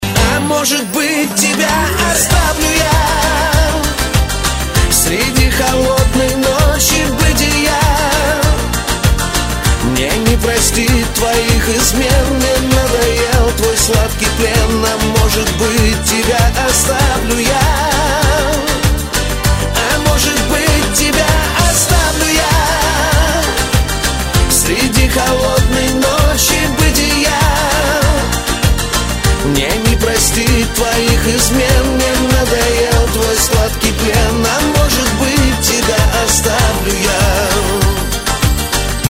Шансон, Авторская и Военная песня